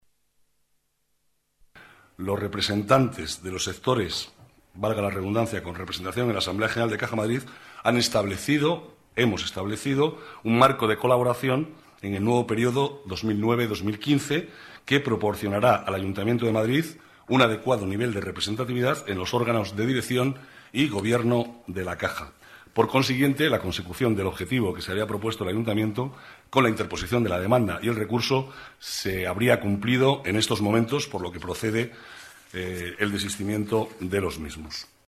Nueva ventana:Declaraciones del vicealcalde, Manuel Cobo: Retirados los recursos en Caja Madrid